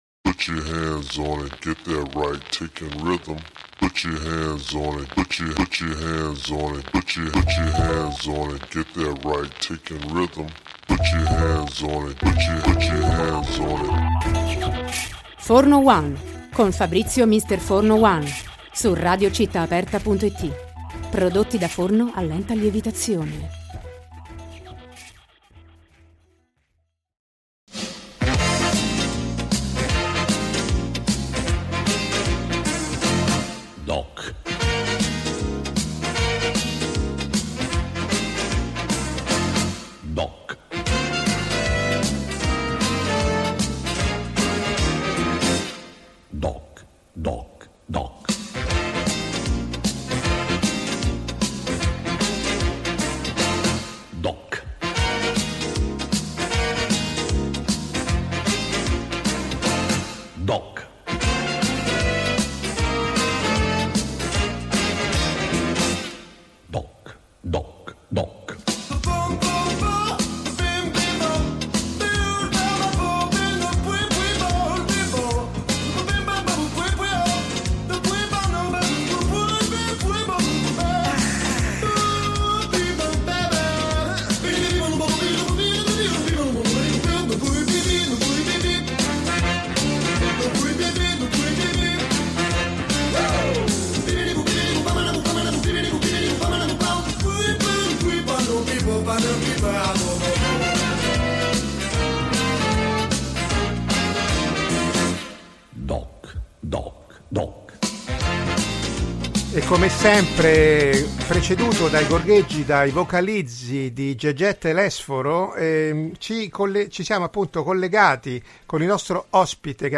Ascolti DOC intervista